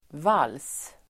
Uttal: [val:s]